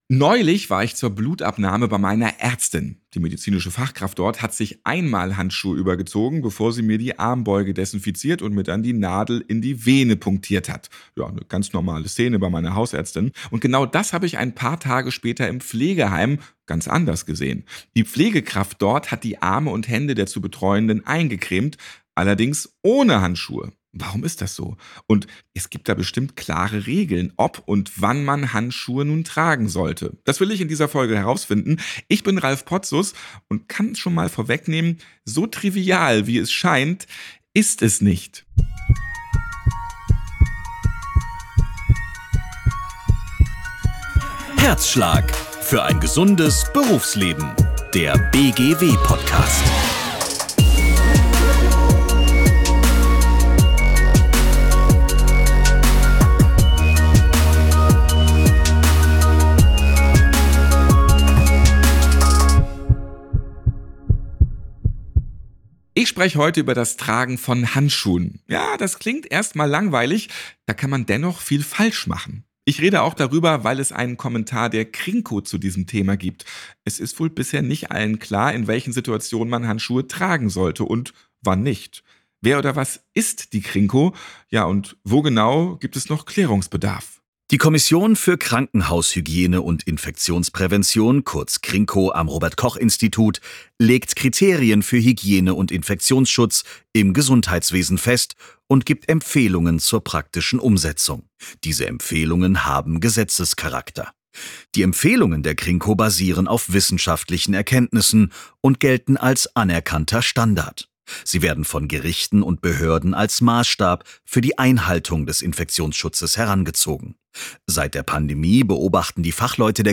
Im Fokus steht das Arbeitsfeld Pflege. Zu Wort kommen Fachleute, Arbeitgeberinnen und Arbeitgeber, Führungskräfte und Beschäftigte.